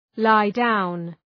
lie-down.mp3